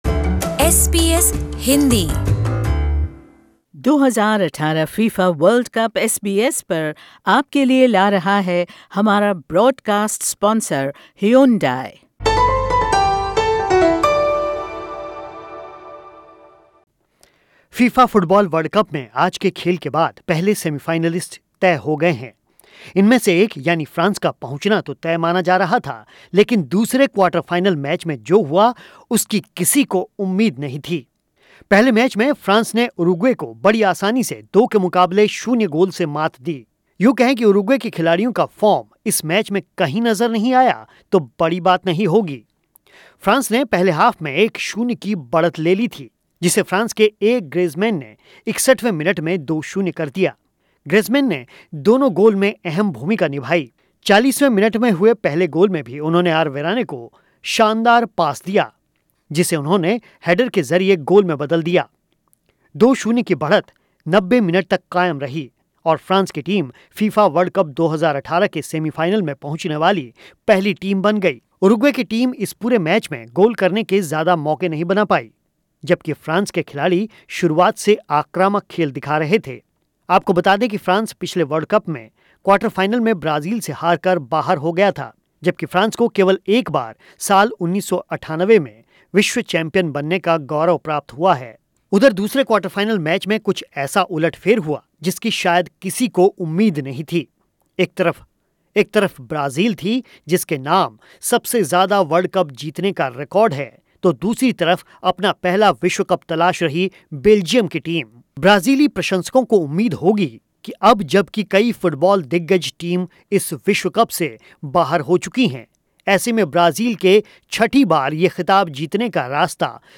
वर्ल्ड कप 2018 के क्वार्टर फाइनल में एक और बड़ा उलटफेर देखने को मिला है. 5 बार के चैंपियन ब्राज़ील को बेल्ज़ियम के हाथों हारकर बाहर होना पड़ा है. वहीं फ्रांस 2006 के बाद पहली बार सेमीफाइनल में जगह बनाने में कामयाब हुआ है. विश्वकप 2018 के 22 वें दिन के खेल पर सुनिए ये ख़ास रिपोर्ट.